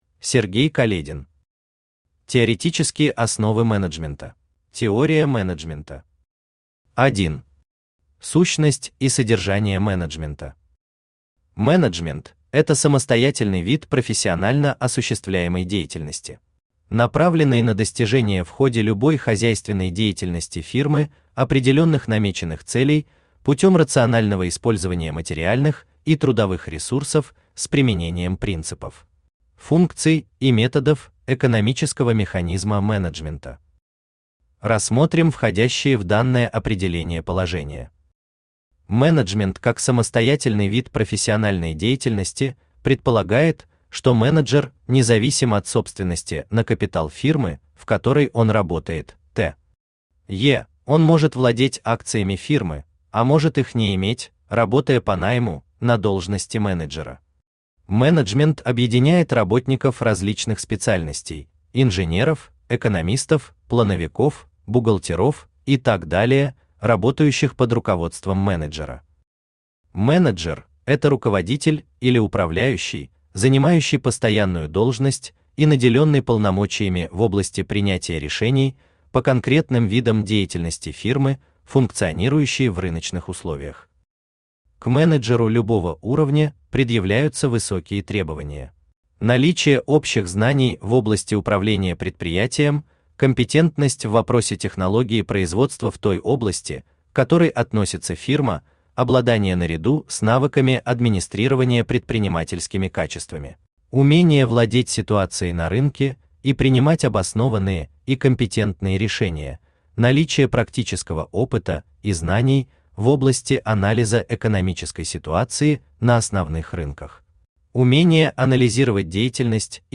Аудиокнига Теоретические основы менеджмента | Библиотека аудиокниг
Aудиокнига Теоретические основы менеджмента Автор Сергей Каледин Читает аудиокнигу Авточтец ЛитРес.